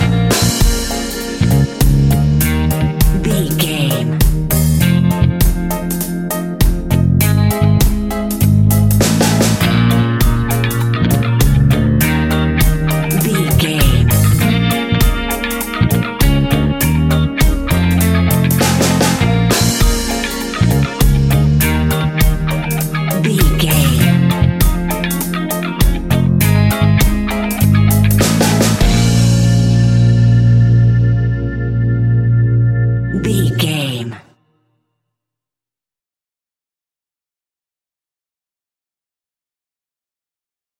Aeolian/Minor
B♭
dub
laid back
chilled
off beat
drums
skank guitar
hammond organ
percussion
horns